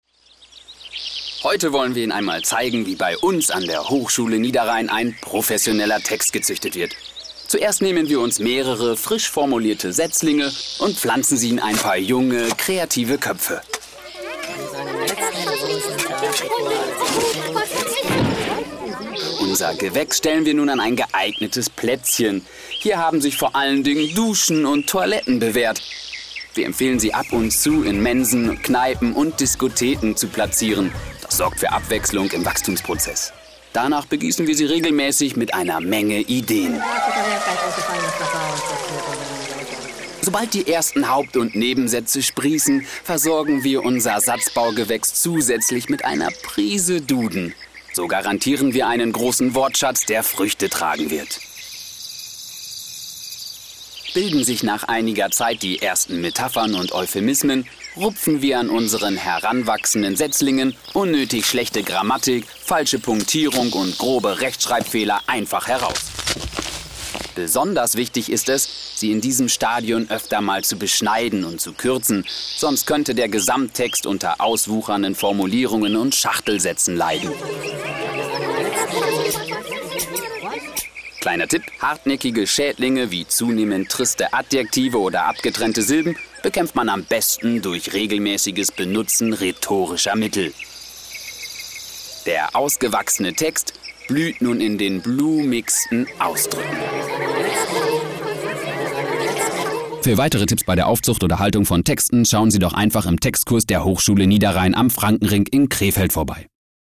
Am Dienstag hatten wir nun die Möglichkeit unsere Radiospots im Sprachlabor in Düsseldorf mit professionellen Sprechern aufzunehmen.
Radiospot – Lange Version – 1,6 MB / MP3(rechte Maustaste/Speichern unter…)
Am Ende war sie mir aber etwas zuuu lang und schleppend, so dass ich mir zu Hause nochmal eine kurze Version zusammengeschnitten habe.